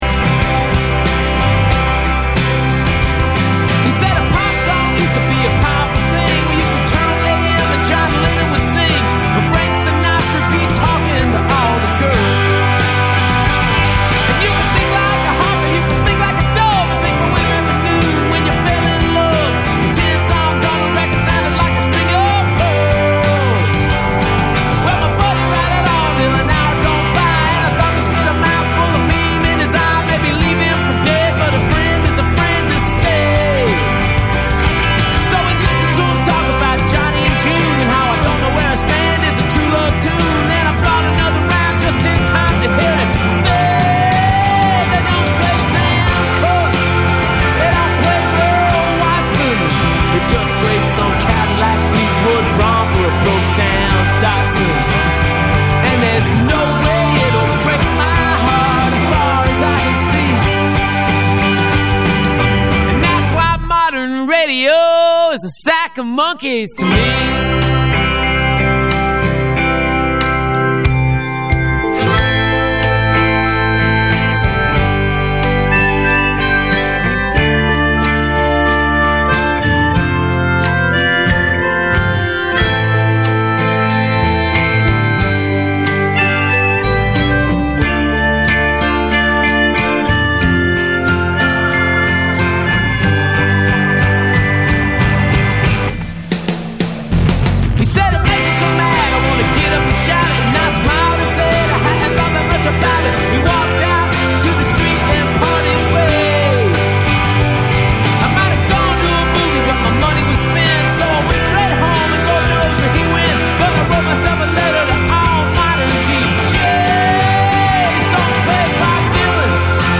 show featuring musical guests
LIVE